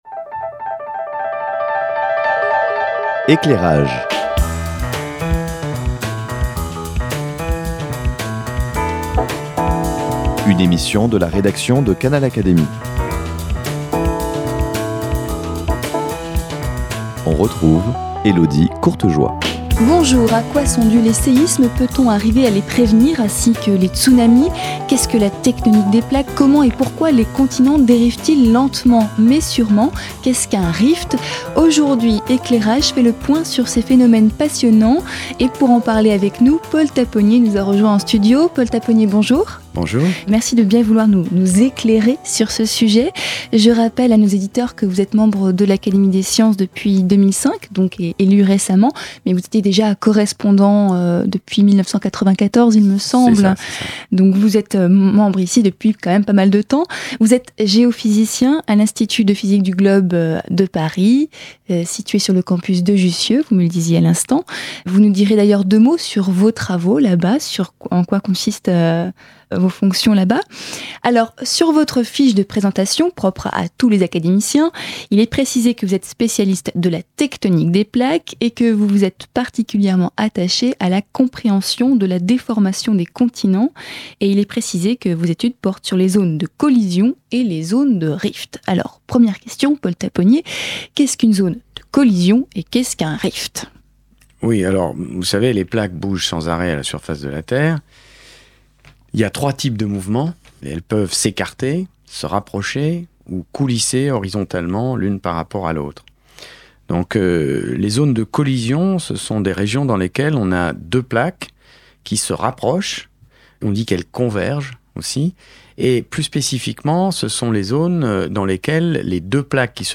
Comment et pourquoi bougent-elles ? Arriverons-nous dans les trente prochaines années à prédire les grands séismes grâce au GPS ? Réponses avec le géologue et géophysicien Paul Tapponnier, membre de l’Académie de sciences.